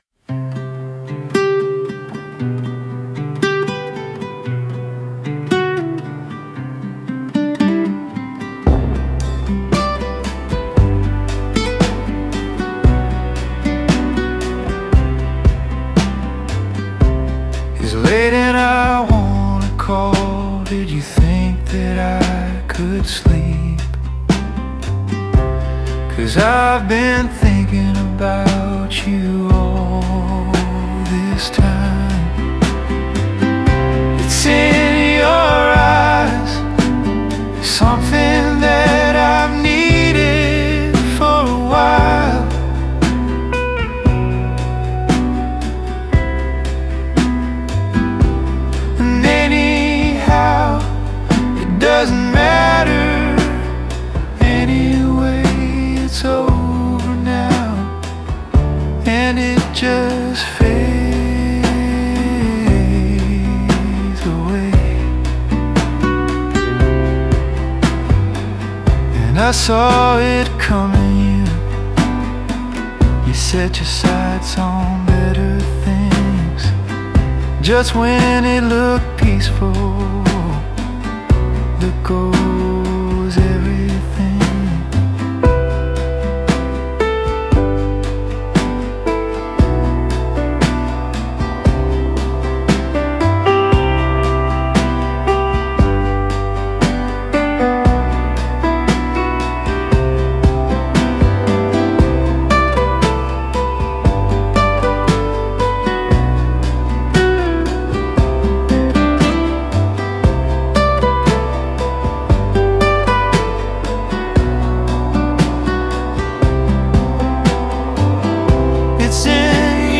• BPM: Approximately 72 BPM.
• Style: Intimate, melodic, and building in intensity.
• Genre: Piano Pop / Adult Contemporary / Indie Pop.